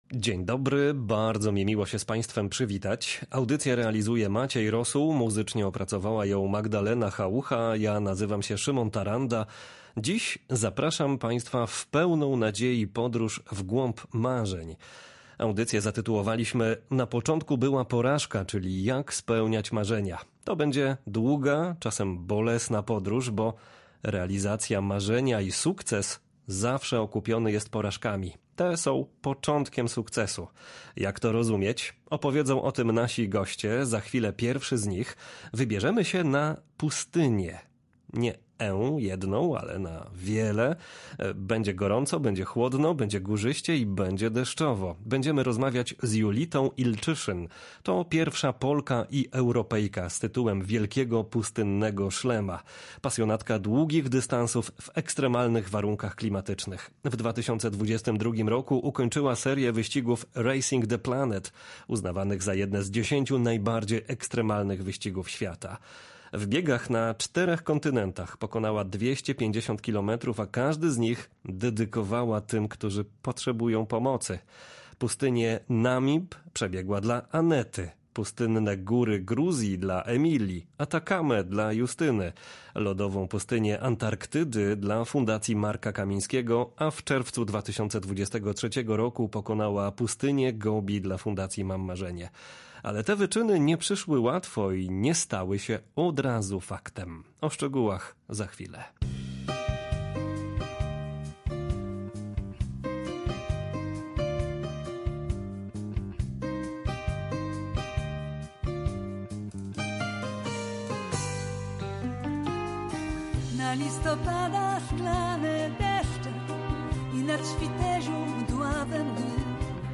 Opowiedzą o tym nasi goście.